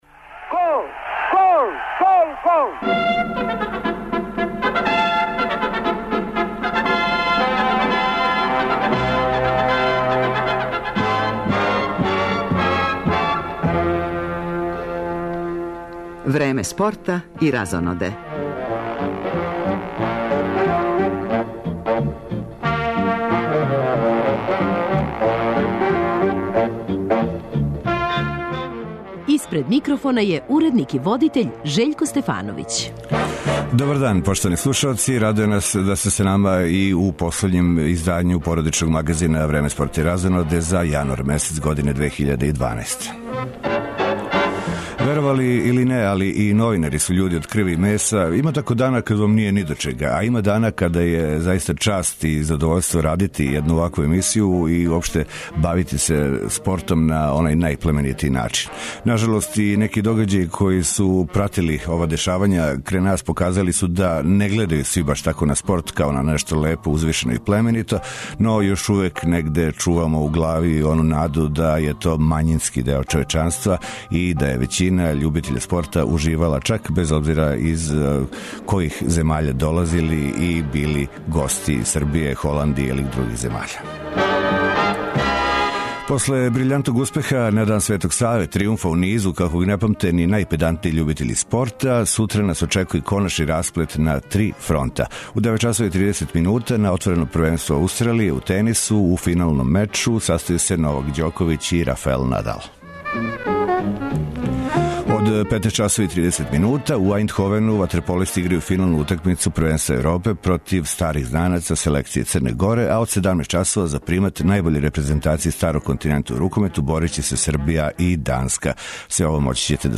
Потпуно разумљиво, ово ће бити и централне теме овог издања емисија Време спорта и разоноде, уз изјаве актера ових догађаја, реконструисаћемо учињено и видети какве су реалне шансе за потпуни тријумф спортиста Србије у великим финалима.